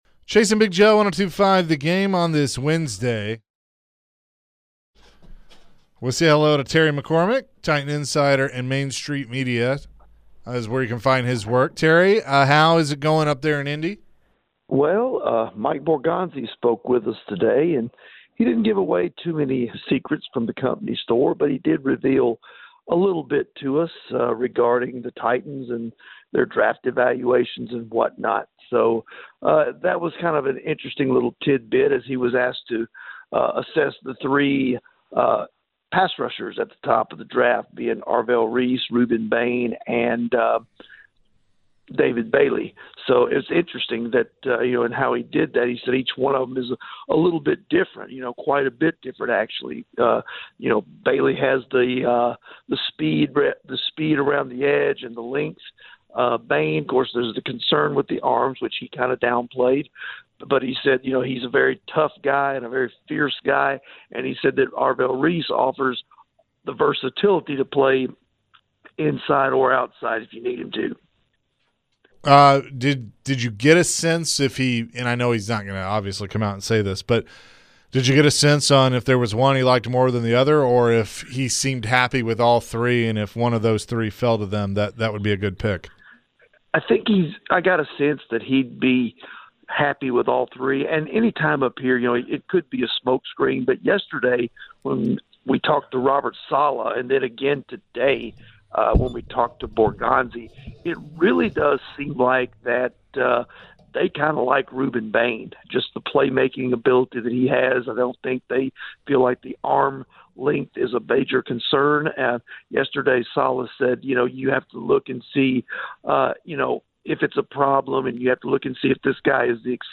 joined the show live from the combine.